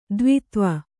♪ dvitva